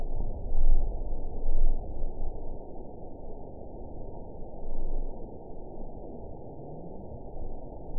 event 911413 date 02/25/22 time 06:06:12 GMT (3 years, 2 months ago) score 9.18 location TSS-AB01 detected by nrw target species NRW annotations +NRW Spectrogram: Frequency (kHz) vs. Time (s) audio not available .wav